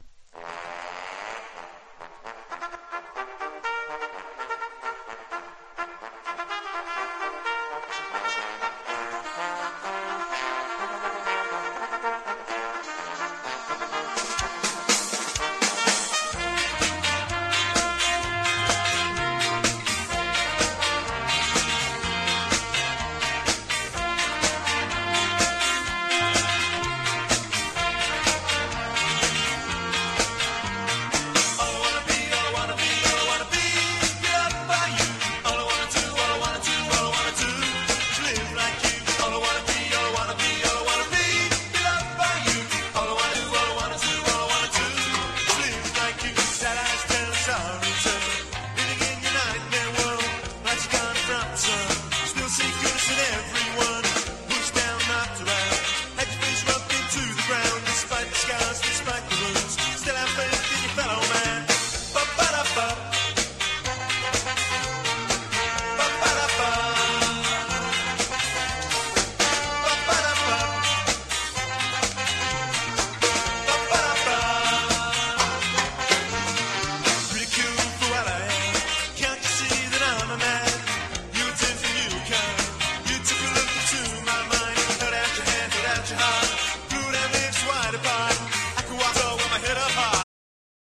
2 TONE / SKA